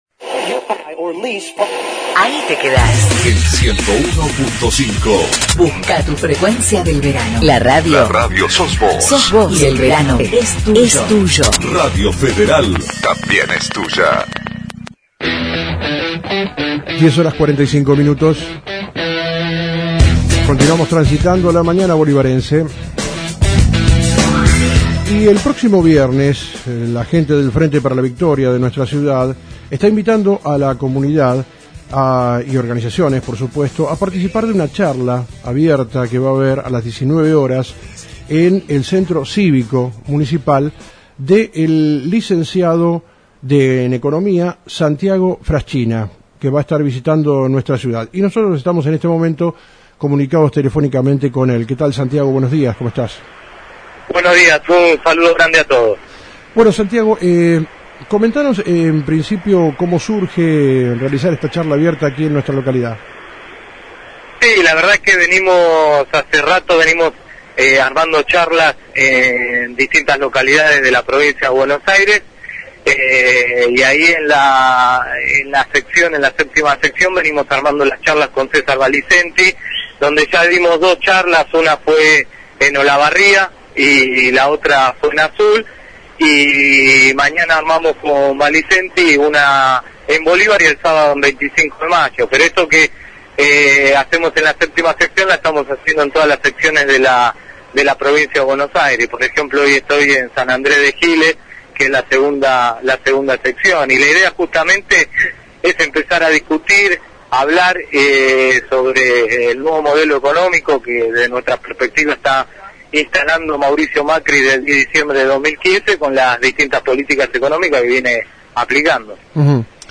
Dialogamos con el Economística que Mañana brinda una Charla en Bolíva